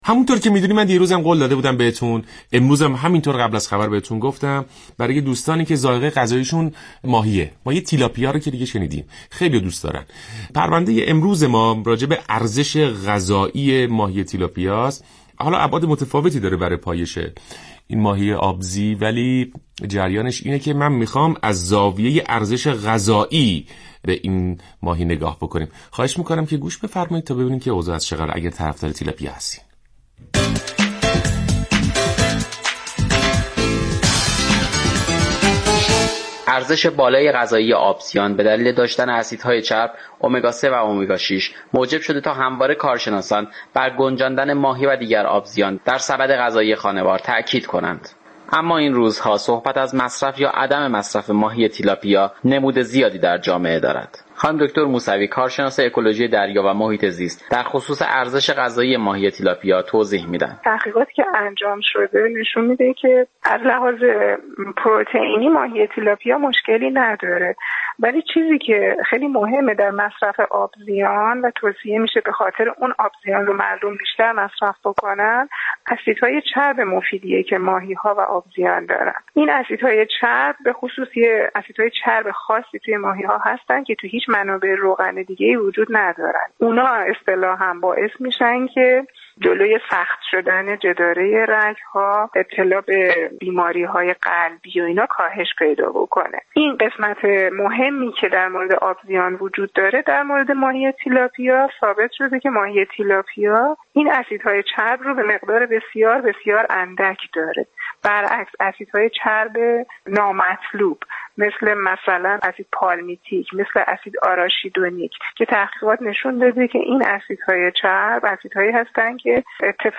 برنامه رادیو ایران درباره مضرات مصرف ماهی تیلاپیا